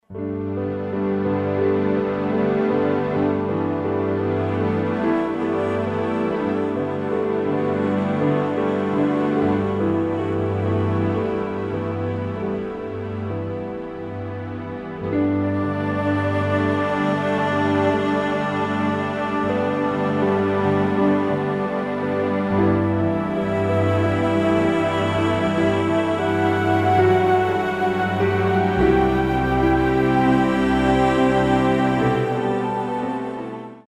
utilizando mais instrumentos percussivos e vozes